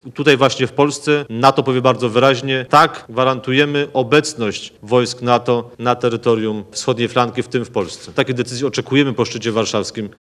– mówi prezydencki minister Krzysztof Szczerski.